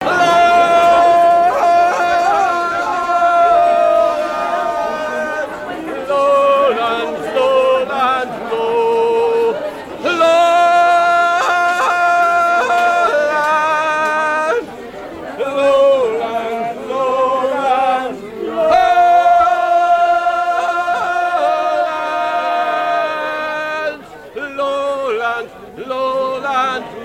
Chants anglais
gestuel : à hisser à grands coups
circonstance : maritimes
Pièce musicale inédite